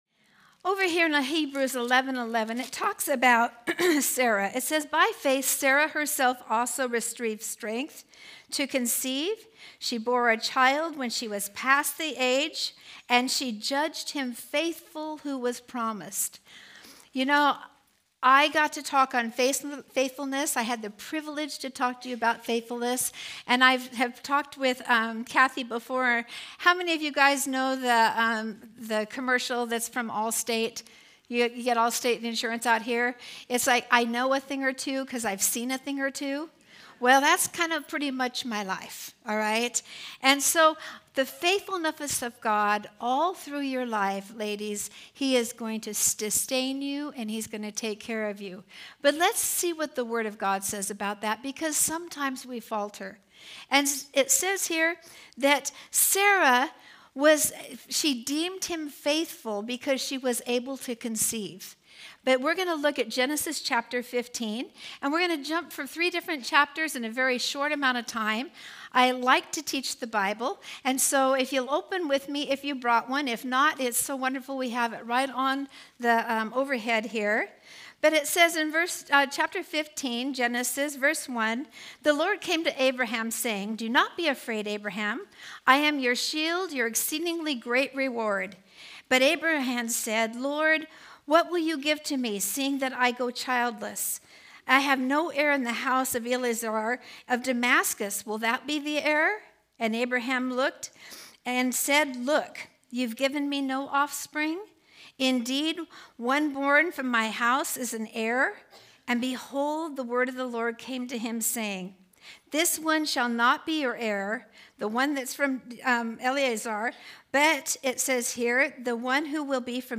Home » Sermons » Fruit of Faithfulness
2026 DSWG Conference: Women's Gathering Date